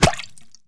bullet_impact_water_06.wav